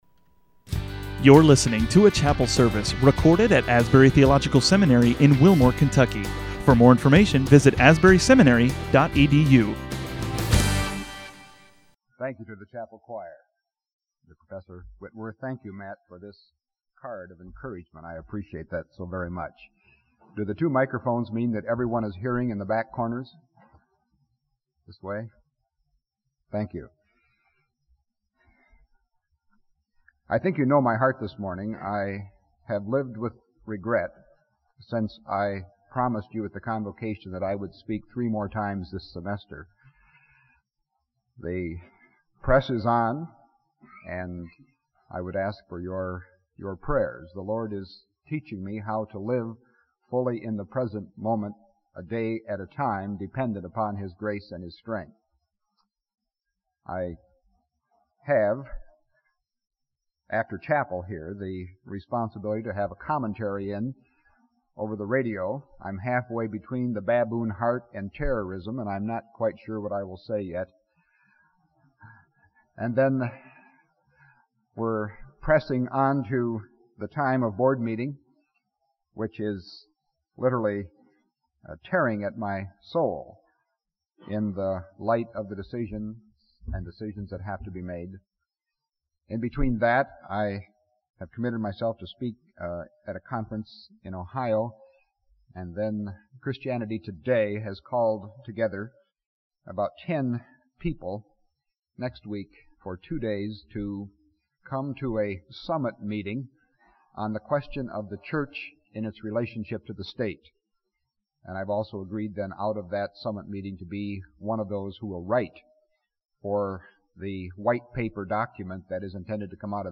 Faculty chapel services, 1984